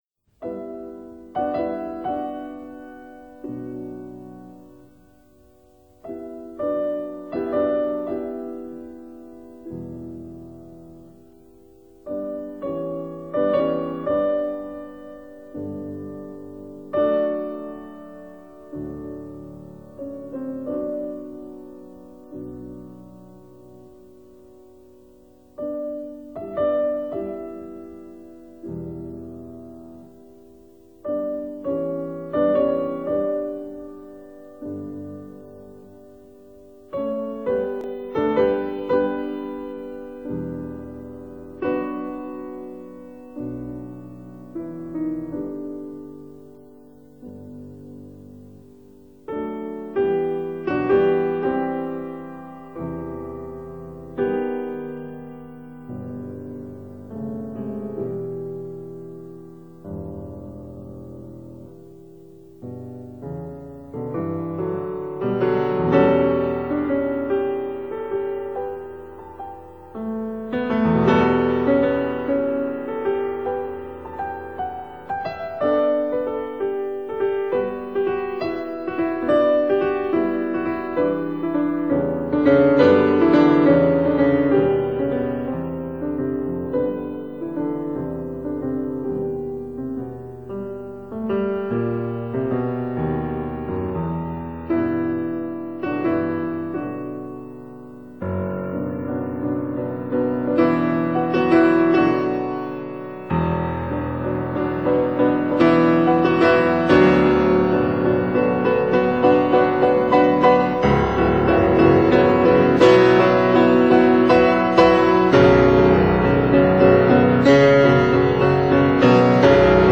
modern piano
Recording venue: Springhead School, Northfleet, Kent, UK